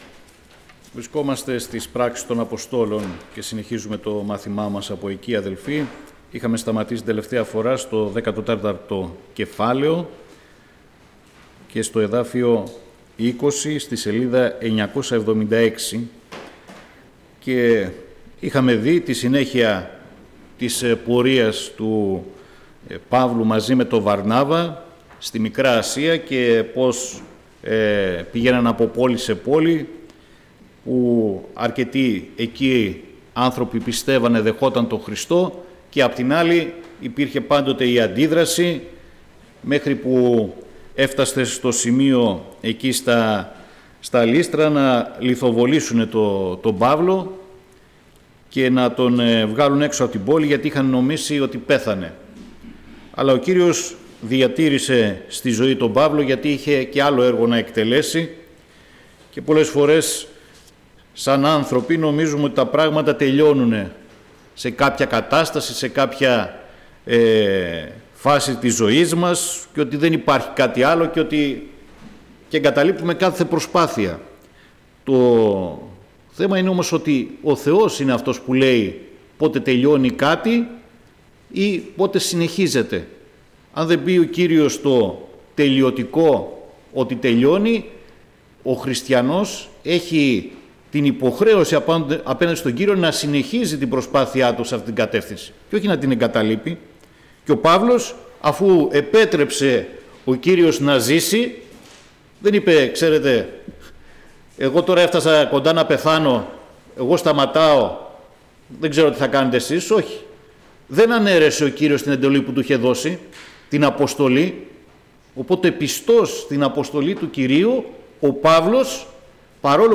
Ομιλητής: Διάφοροι Ομιλητές